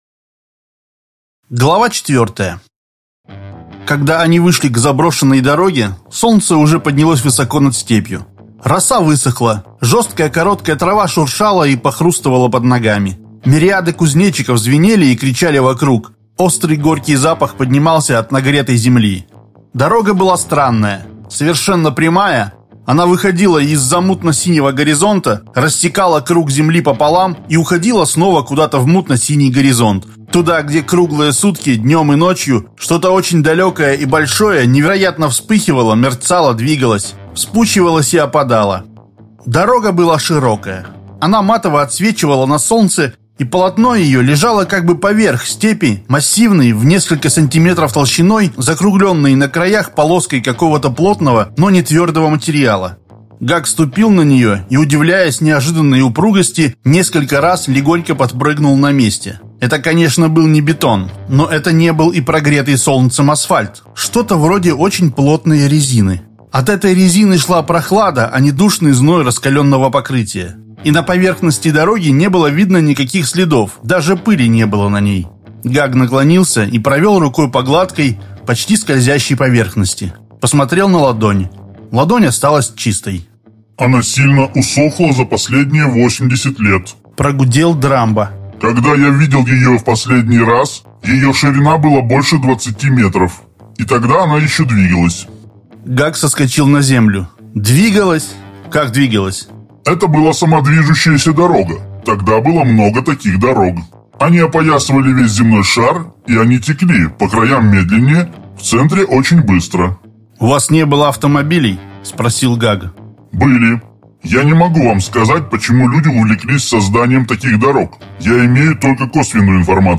Аудиокнига Парень из преисподней. Часть 4.
На этой странице выложена часть № 4 аудиокниги «Парень из преисподней» по одноименному произведению братьев Стругацких.